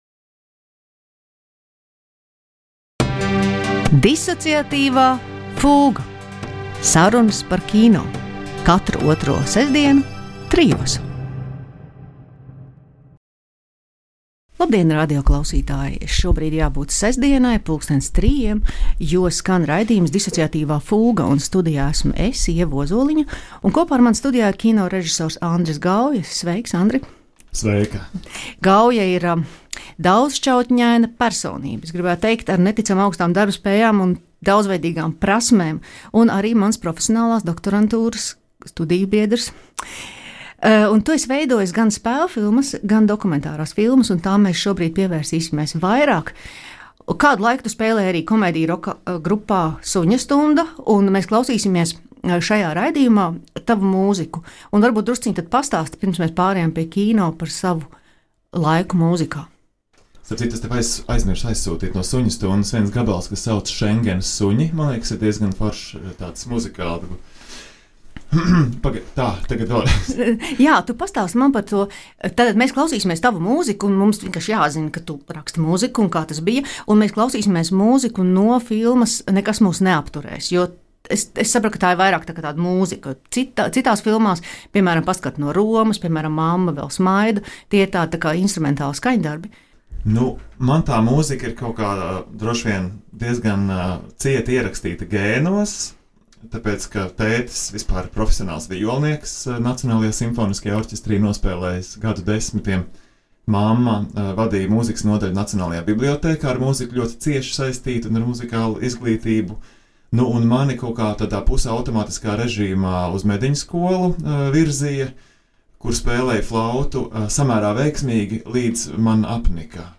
Ieraksts tapis Rīgas Kinomuzejā.